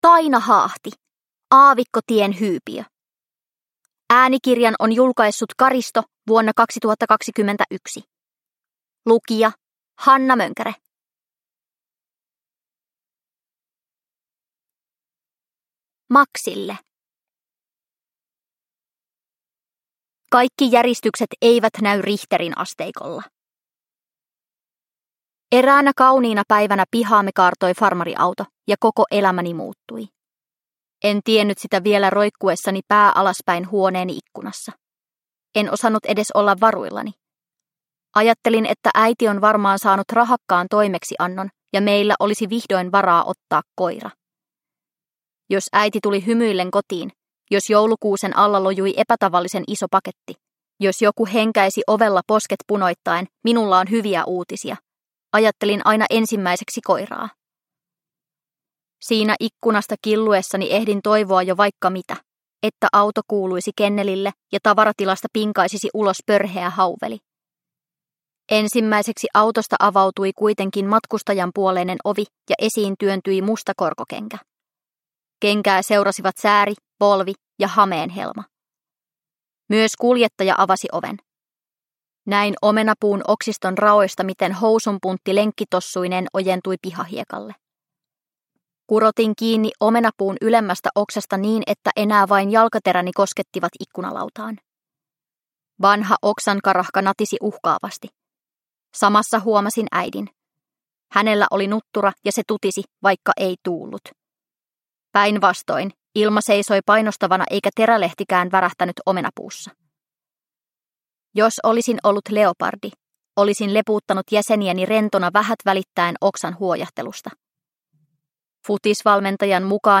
Aavikkotien hyypiö – Ljudbok – Laddas ner